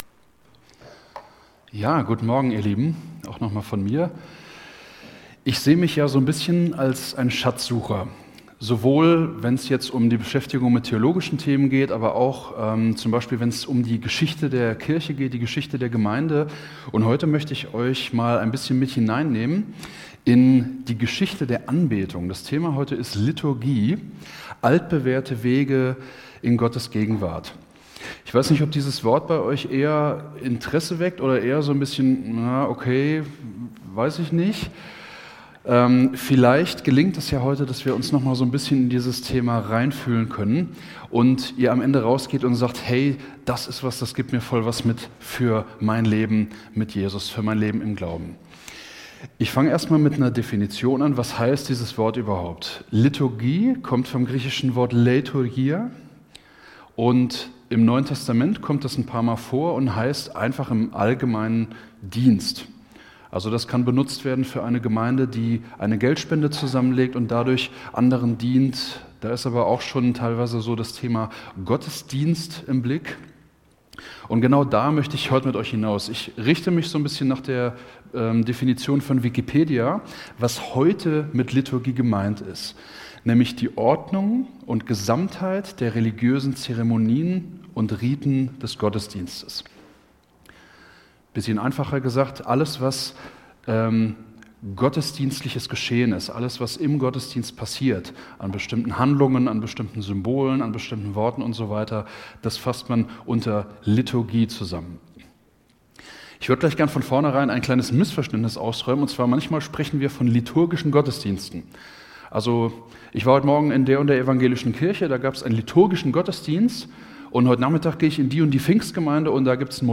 Marburger Predigten